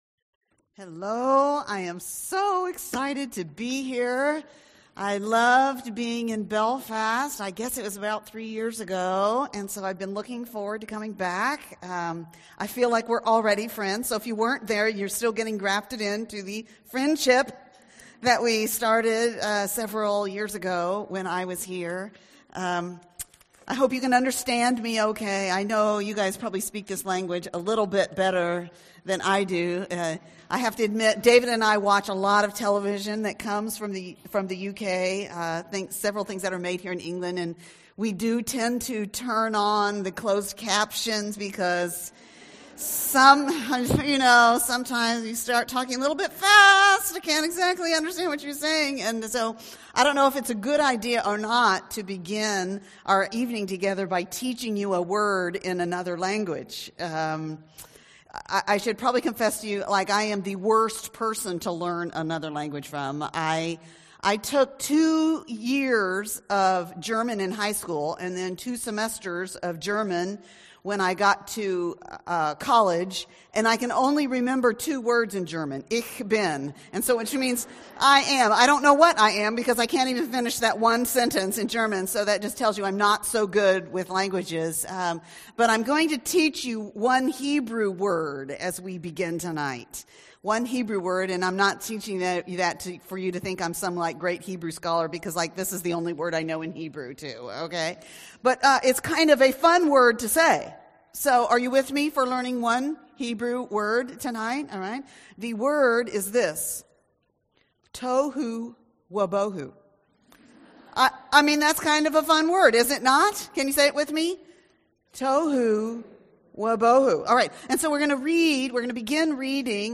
catch up on the talks from our BW Spring Conference 2019 'Even Better Than Eden'